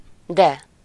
Ääntäminen
IPA: /ˈdɛ/